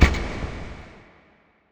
c_spiker_hit1.wav